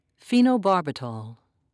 (fee-noe-bar'bi-tal)